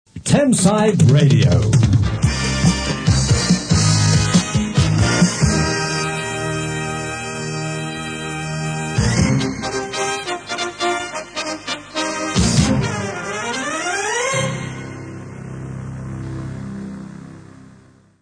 Short theme ident 1985